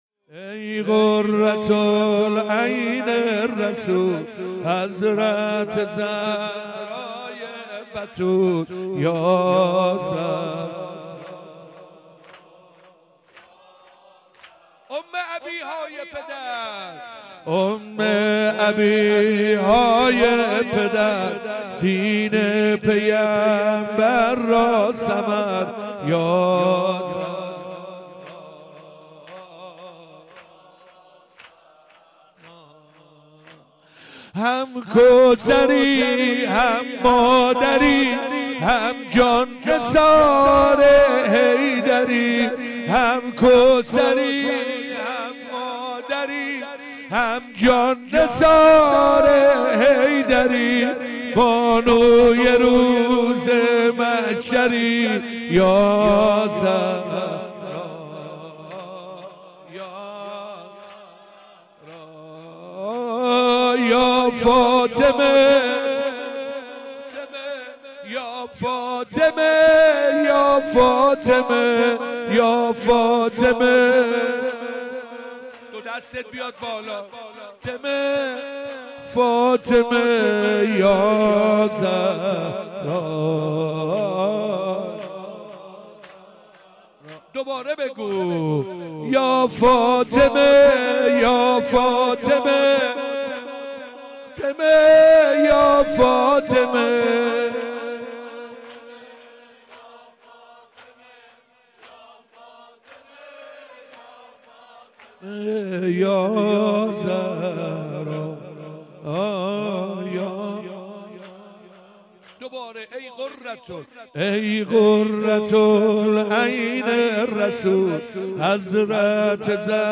7 بهمن 97 - محبان المرتضی - زمینه - ای قرت العین رسول